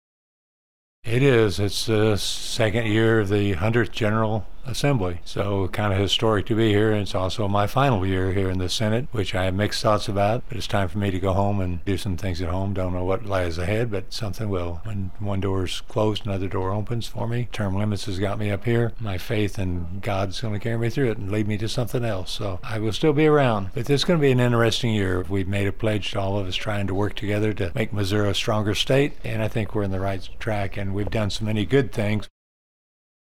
1. Senator Cunningham says the Missouri legislative session is underway.